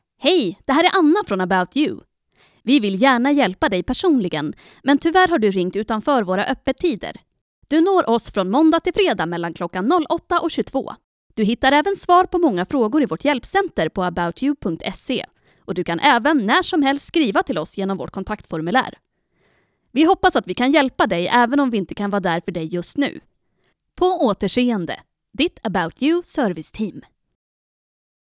Female
Character, Confident, Natural, Warm, Witty, Versatile, Approachable, Conversational, Corporate, Energetic, Funny, Young
Northern Swedish (native). General Swedish.
E-learning.mp3
Microphone: Neumann TLM 103
Audio equipment: Voice booth